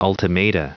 Prononciation du mot ultimata en anglais (fichier audio)
Prononciation du mot : ultimata